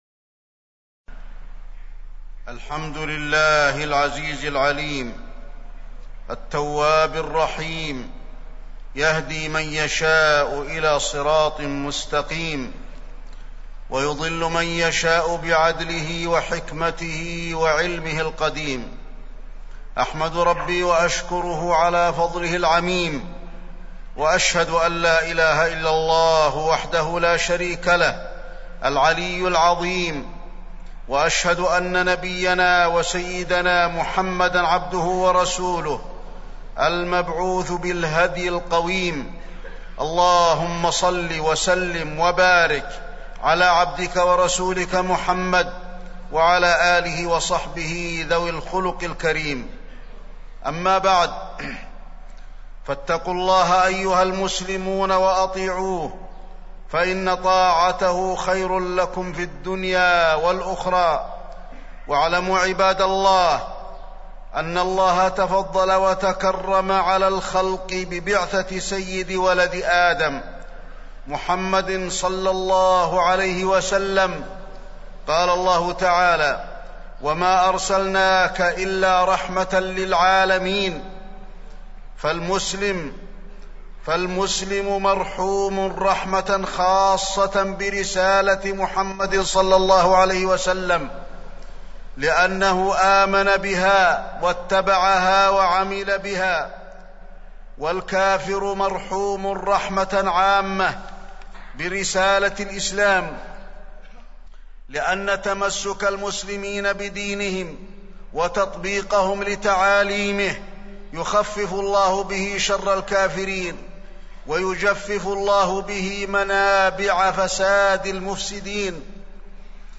تاريخ النشر ١٠ جمادى الأولى ١٤٢٦ هـ المكان: المسجد النبوي الشيخ: فضيلة الشيخ د. علي بن عبدالرحمن الحذيفي فضيلة الشيخ د. علي بن عبدالرحمن الحذيفي التمسك بالسنة